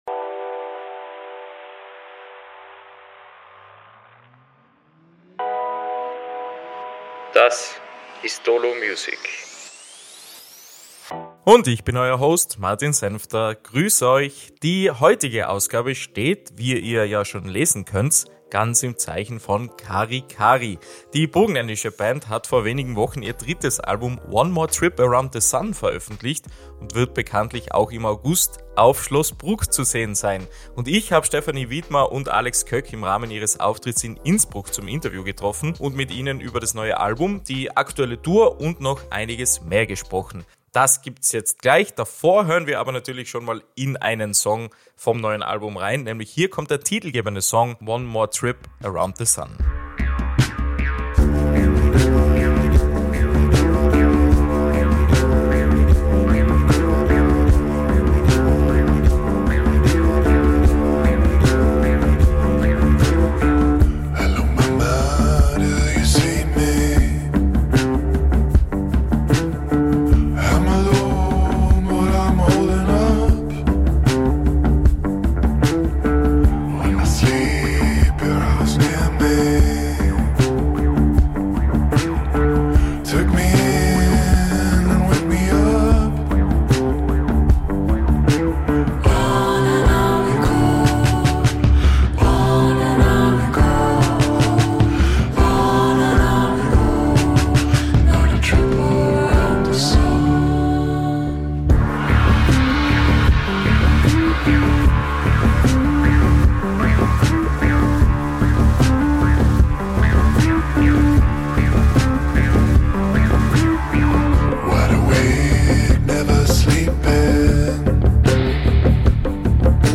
Natürlich gibt's auch Musik zu hören – unter anderem den titelgebenden Track und weitere Songs aus dem neuen Album.